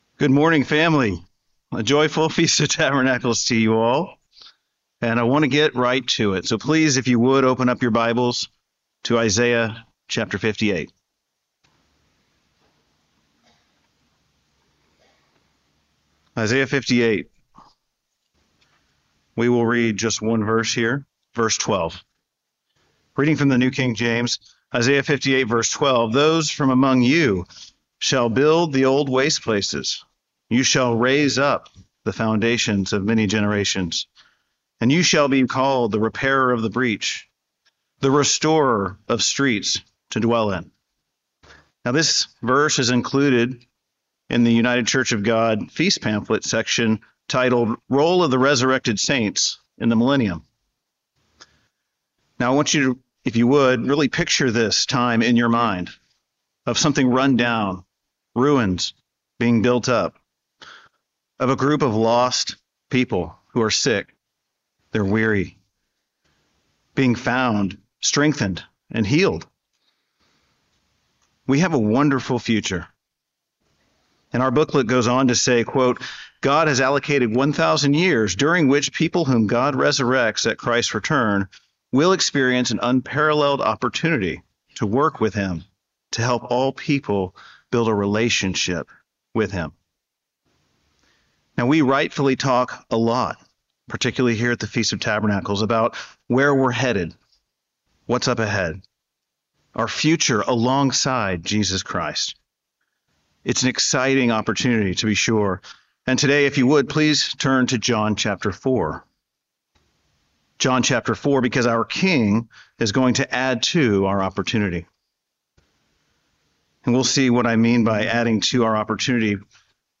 Sermons
Given in Aransas Pass, Texas